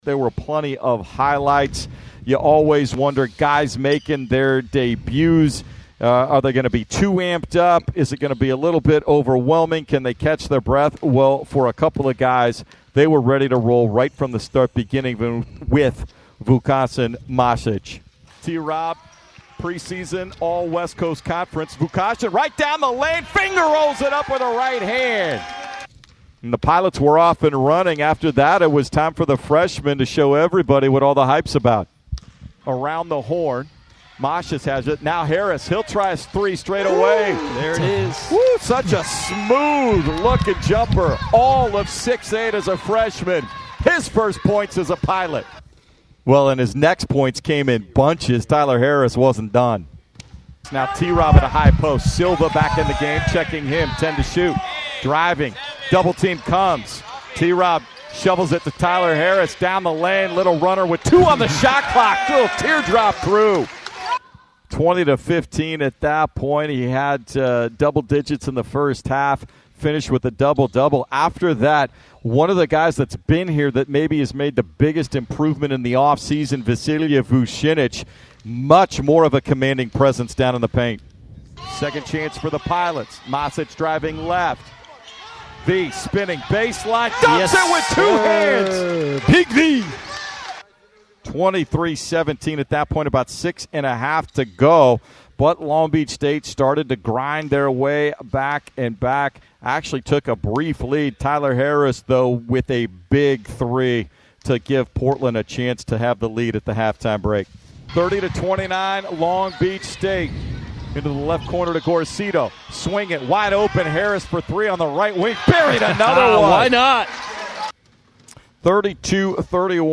Men's Hoops vs. Long Beach State Radio Highlights